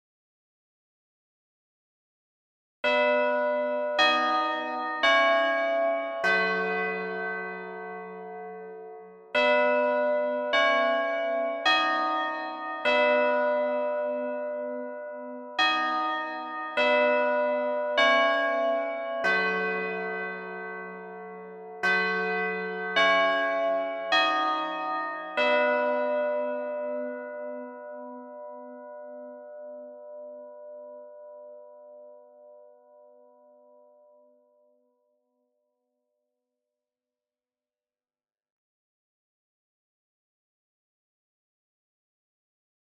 定時を刻む近所の学校のチャイムが聞こえてくる前に、私は想像するチャイムのメロディーをチューブベルで演奏した。
大袈裟なベルのサウンドに対し、ホール系のカテドラルを模したリバーブを付加した。
荘厳なる音響が耳を劈いた。
レコーディング実験
レコーディング及びミキシングは24bit/96kHz。
LEXICONのプラグインリバーブ（大聖堂プリセット）を付加。音圧上げ無し。
samplechime.mp3